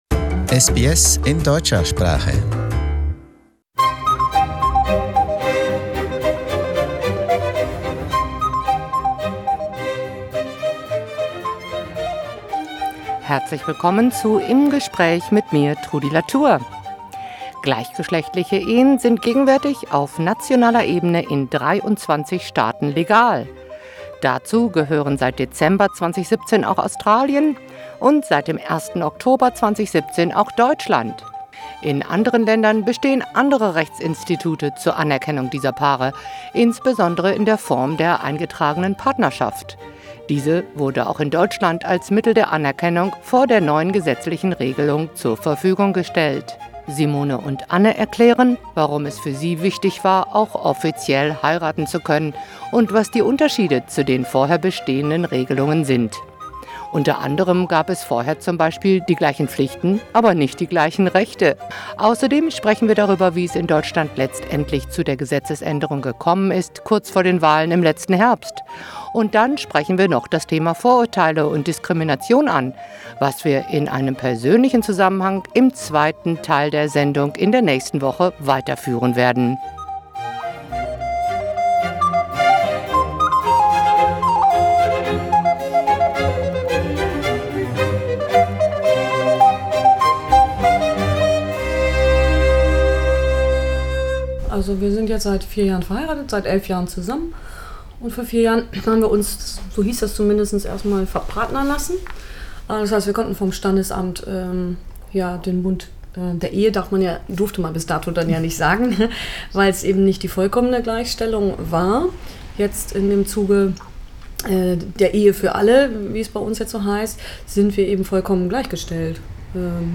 Im Gespräch: Ehe für Alle